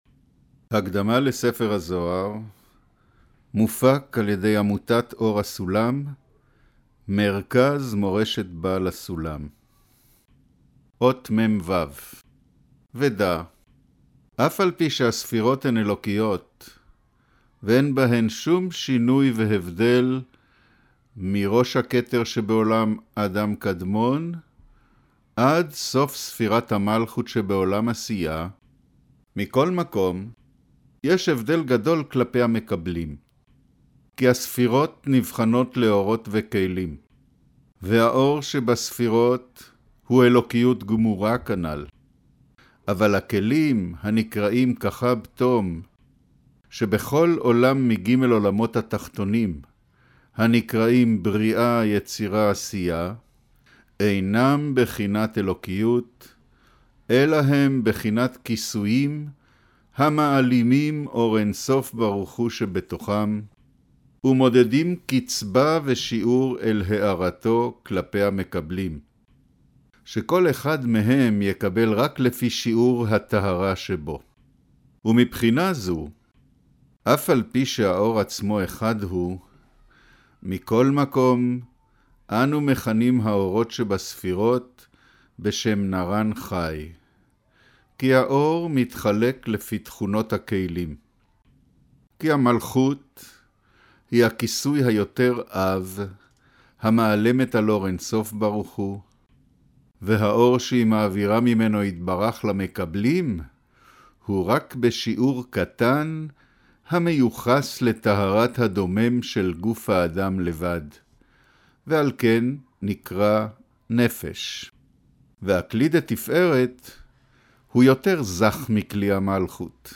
אודיו - קריינות הקדמה לספר הזהר אות מו' - סד'